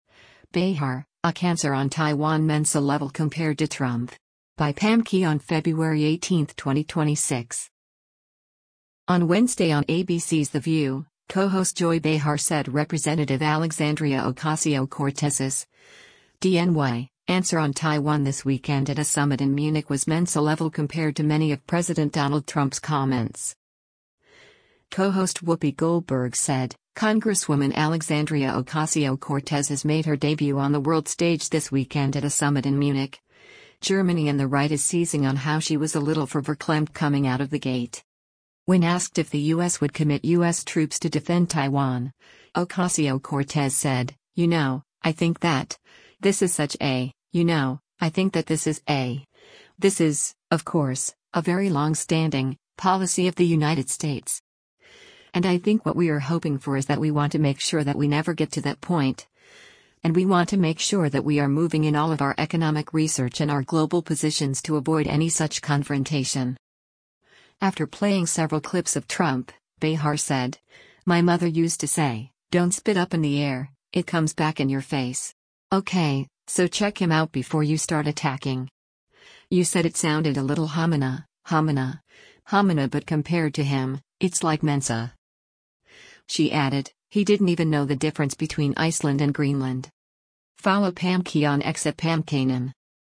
On Wednesday on ABC’s “The View,” co-host Joy Behar said Rep. Alexandria Ocasio-Cortez’s (D-NY) answer on Taiwan this weekend at a summit in Munich was Mensa-level compared to many of President Donald Trump’s comments.